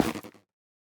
Minecraft Version Minecraft Version 1.21.5 Latest Release | Latest Snapshot 1.21.5 / assets / minecraft / sounds / block / fungus / break1.ogg Compare With Compare With Latest Release | Latest Snapshot